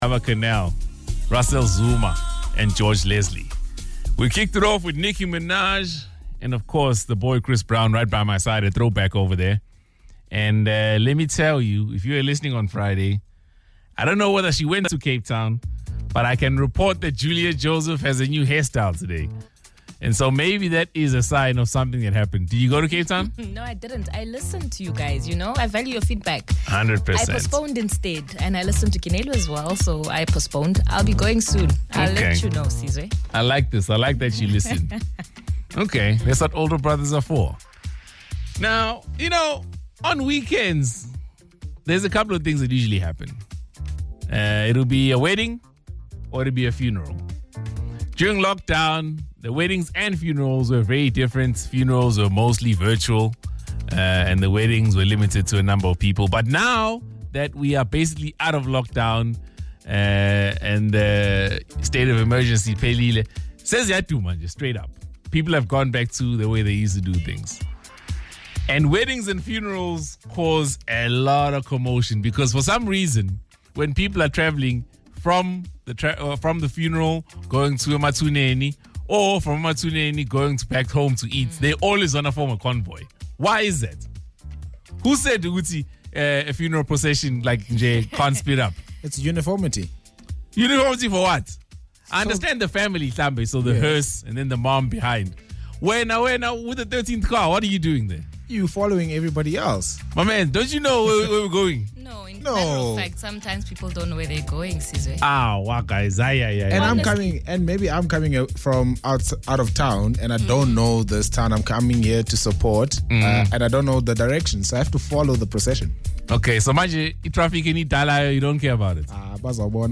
Also read: Kaya Drive: Snubbing food at funerals is frowned upon Listen to the conversation on Kaya Drive: Mon 15:00:38 to 16:01:14 Mon 15:00:38 to 16:01:14 Protocols to observe if you encounter a procession: Yield the right of way.